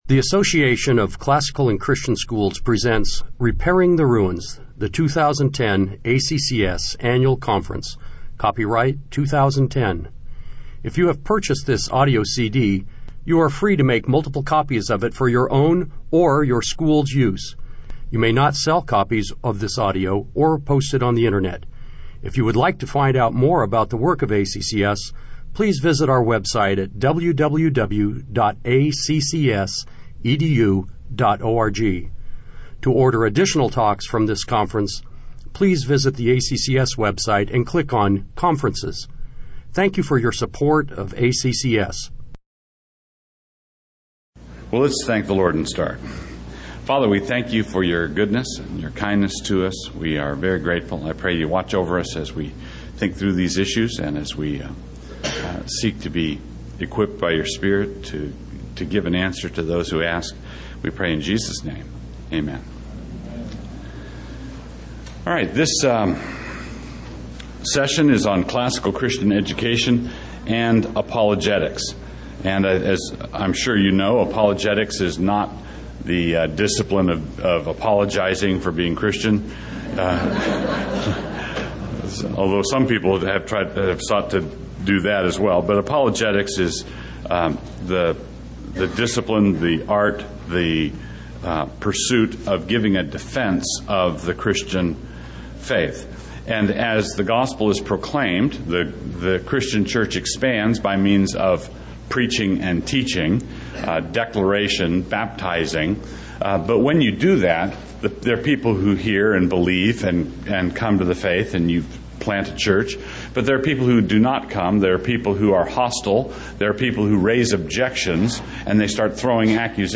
2010 Workshop Talk | 1:01:35 | All Grade Levels, Culture & Faith